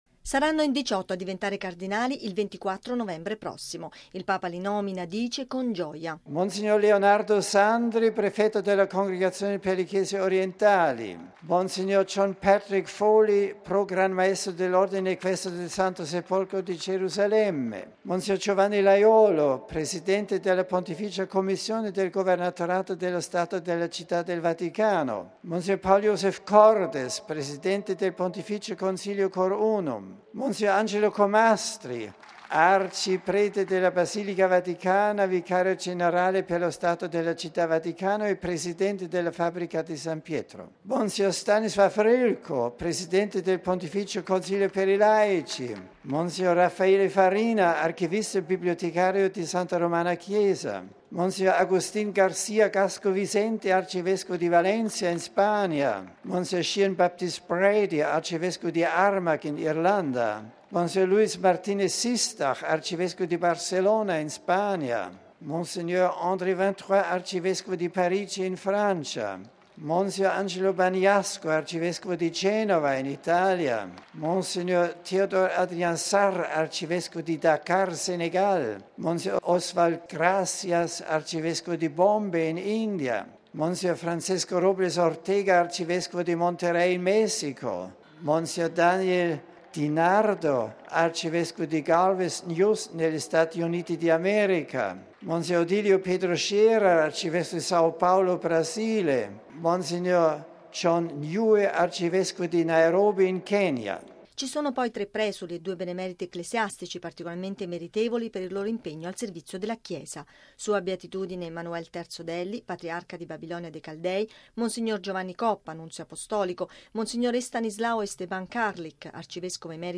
Benedetto XVI nominerà nel Concistoro del 24 novembre 23 nuovi cardinali, l'annuncio all’udienza generale dedicata a Sant’Eusebio. Appello del Papa contro la miseria nel mondo